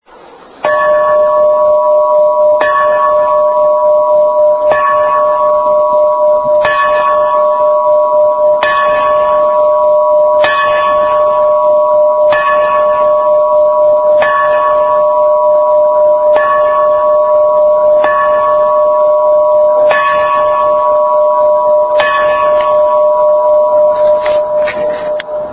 Single Ring (Dong –Dong)